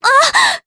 Artemia-Vox_Damage_jp_02.wav